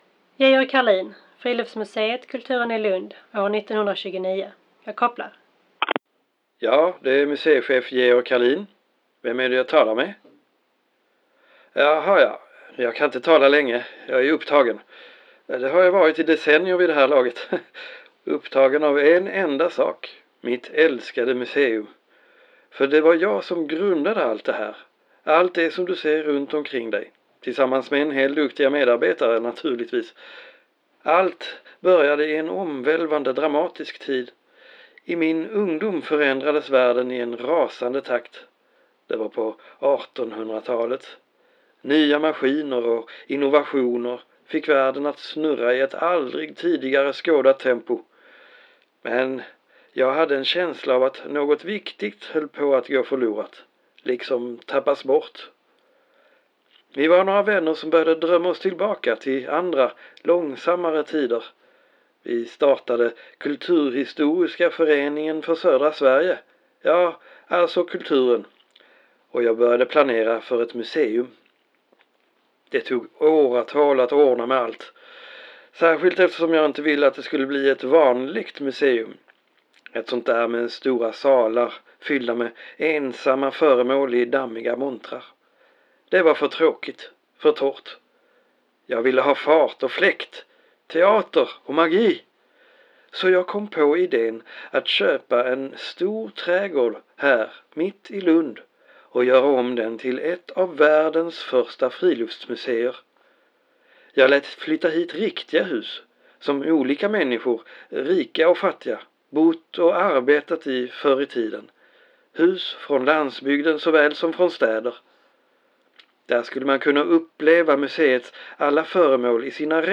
Full av röster från de historiska personerna som en gång bott eller levt i husen på Kulturen.